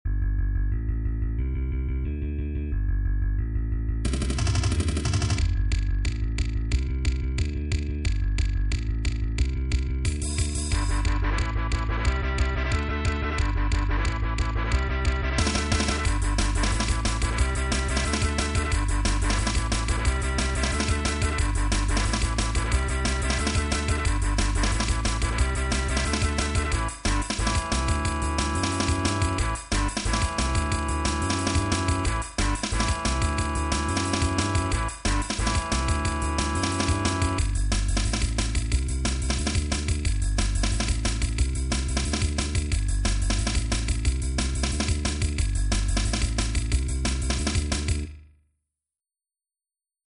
ちょっと成果のほどを・・・うｐ（打ち込み音源）
ちょっとしたライブの始まりかなんかで使えそうな曲。
ベースを目立たせるための曲ｗ